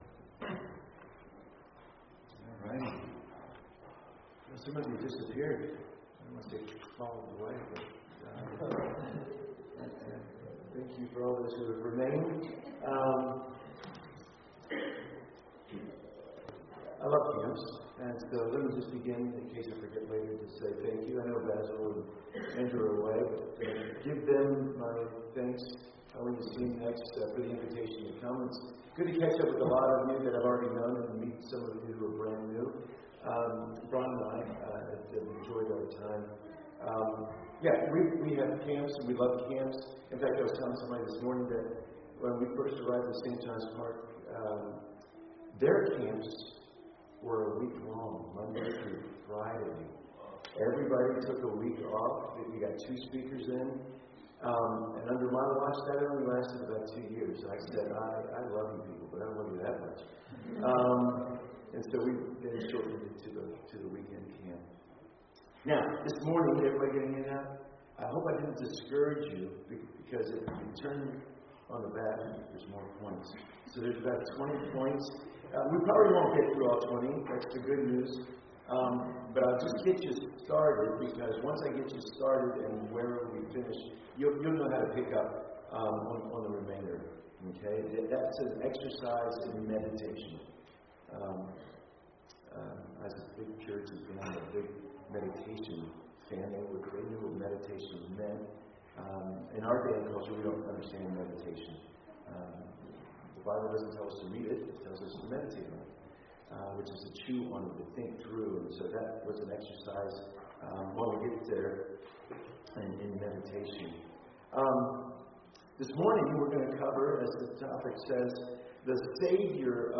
Sermons
SDRBC_Camp-2025_Session-4.mp3